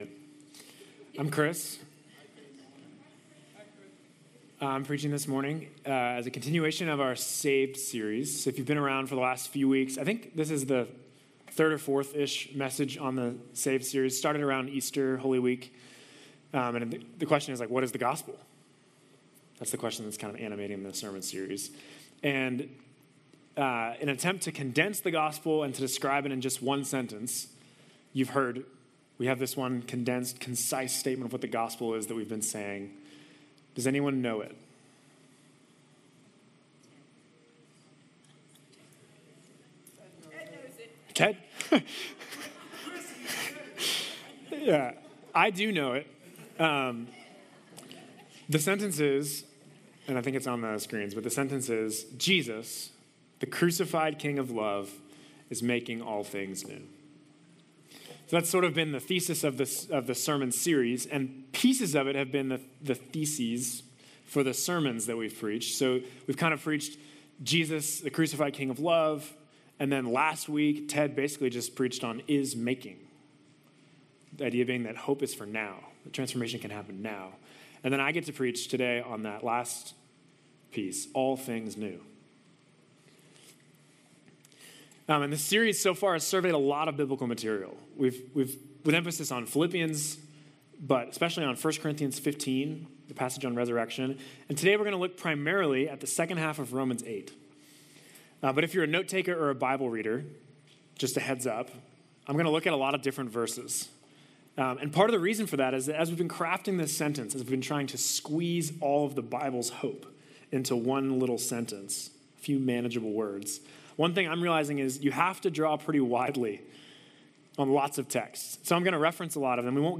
This third sermon in our Saved series deal with the last part of our Gospel summary: Jesus, the crucified king of love is making all things new.